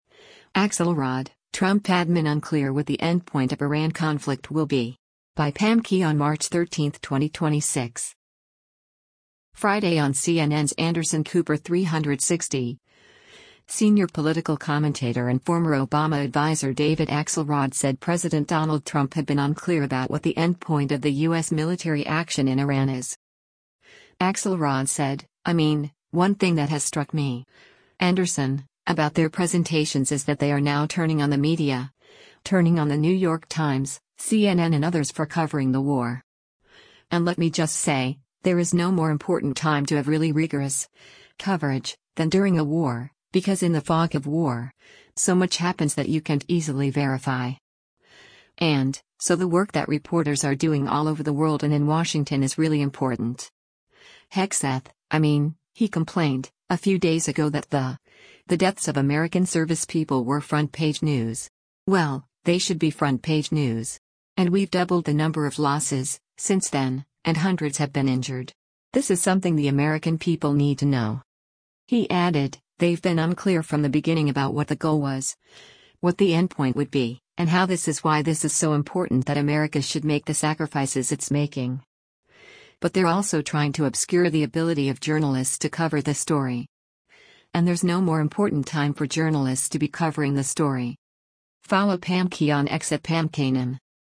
Friday on CNN’s “Anderson Cooper 360,” senior political commentator and former Obama adviser David Axelrod said President Donald Trump had been “unclear” about what the “endpoint” of the U.S. military action in Iran is.